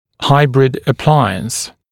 [‘haɪbrɪd ə’plaɪəns][‘хайбрид э’плайэнс]гибридный аппарат